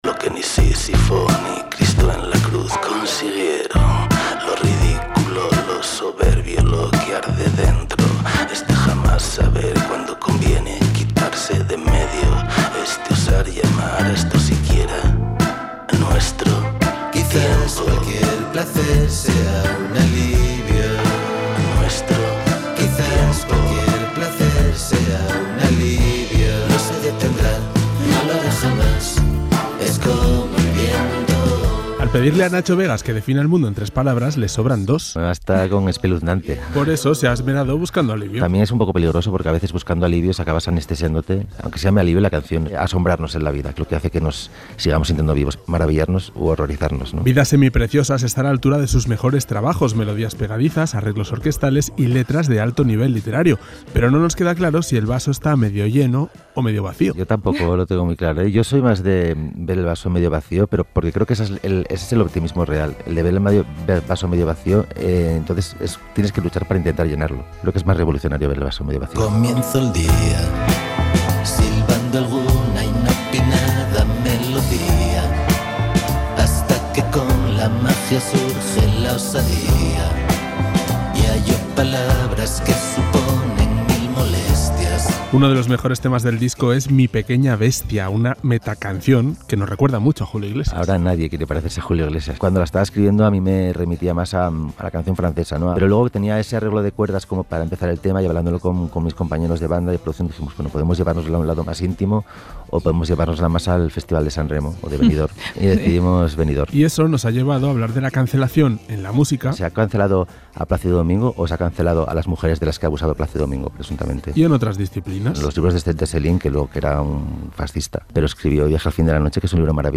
Un repaso al nuevo disco de Nacho Vegas, 'Vidas semipreciosas', con algunas de las mejores anécdotas y reflexiones que de la entrevista concedida a 'Fuego y Chinchetas'. Un reportaje